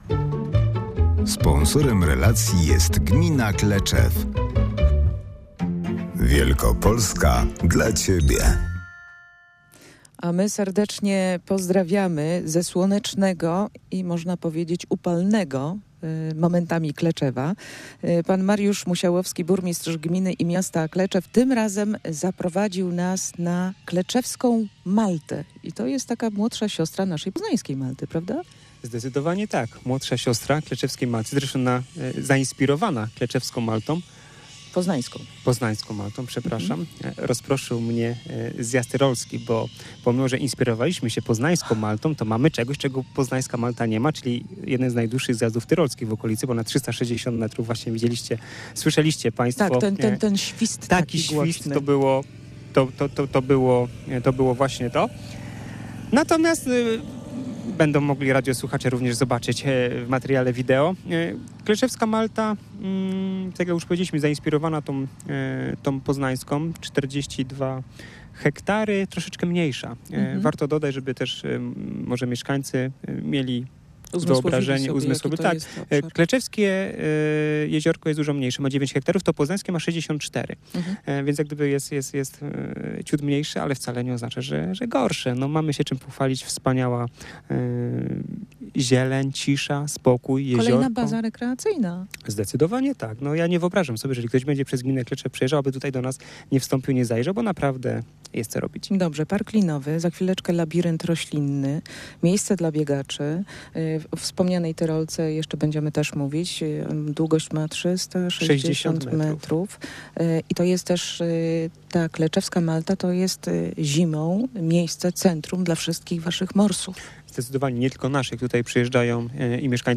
Dziś zawitaliśmy do gminy Kleczew we wschodniej Wielkopolsce.
Kleczewska Malta, atrakcyjnie położony zbiornik wodny na obrzeżach Kleczewa, to młodsza siostra naszej poznańskiej. Burmistrz Gminy i Miasta Kleczew Mariusz Musiałowski opowiedział o tym atrakcyjnym miejscu rekreacyjnym, w którym funkcjonuje park linowy z 360 metrową tyrolką, trasą dla biegaczy i hostelem.